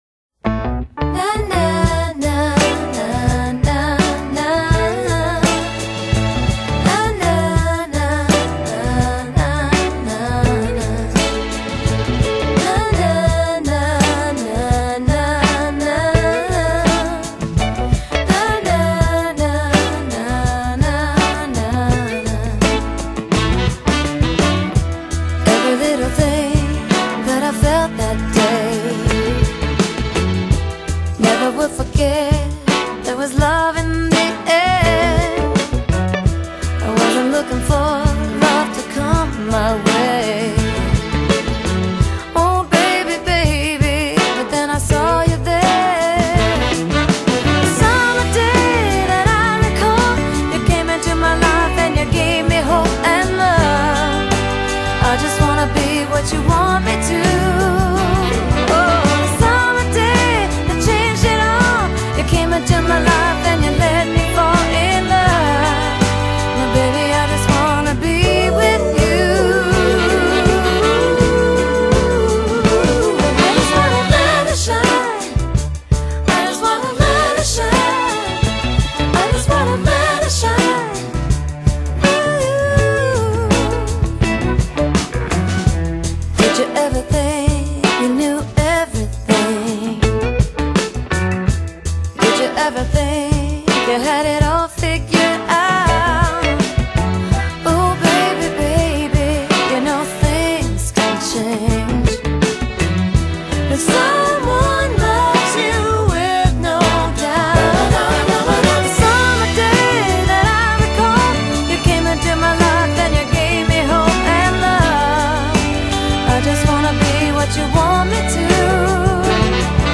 搖滾歌后新碟